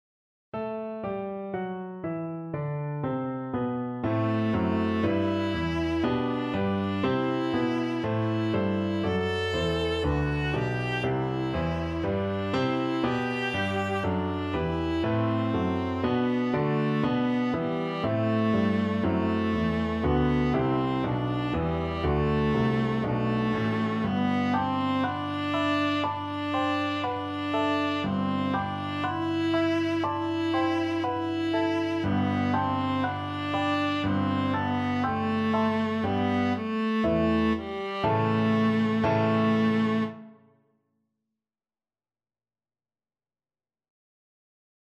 G major (Sounding Pitch) (View more G major Music for Viola )
Moderato maestoso =120
Viola  (View more Easy Viola Music)
Classical (View more Classical Viola Music)